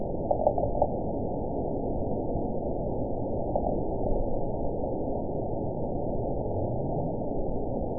event 917402 date 03/31/23 time 12:41:21 GMT (2 years, 1 month ago) score 9.37 location TSS-AB03 detected by nrw target species NRW annotations +NRW Spectrogram: Frequency (kHz) vs. Time (s) audio not available .wav